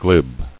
[glib]